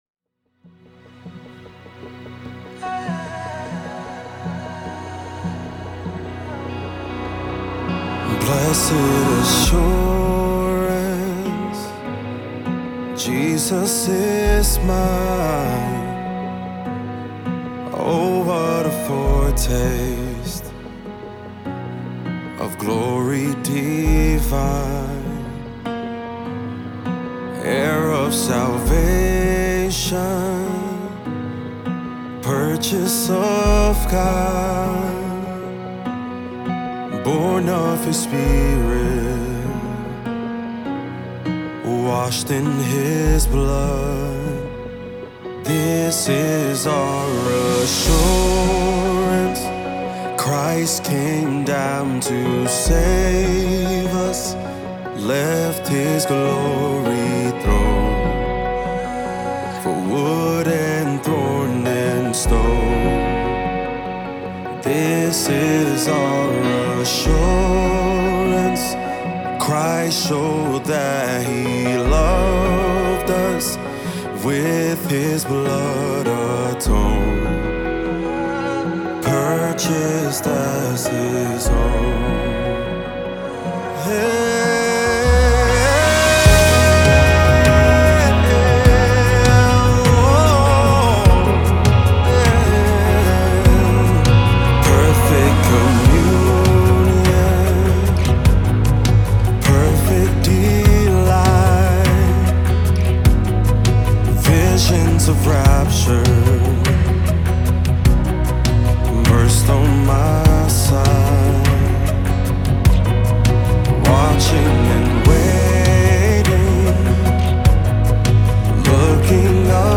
Jesus Worship